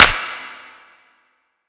DDW4 CLAP 2.wav